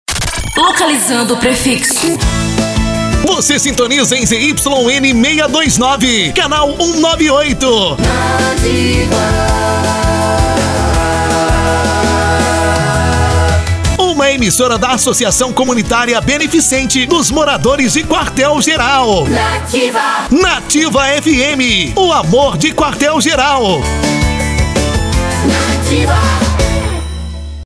prefixo_amor.mp3